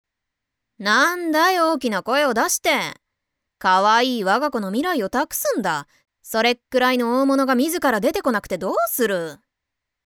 【サンプルボイス】